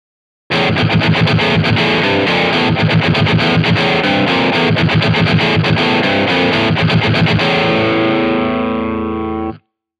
AmpliTube 5 CSはIK Multimedia社が開発したアンプシミュレーターです。無料版ではアンプやエフェクトの数が限られますが、リアルで迫力のあるサウンドを体験できます。